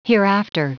Prononciation du mot hereafter en anglais (fichier audio)
Prononciation du mot : hereafter